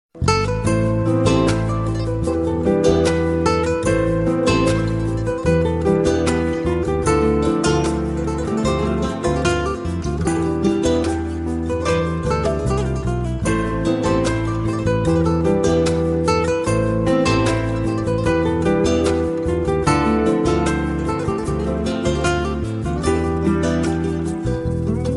Categoria Classiche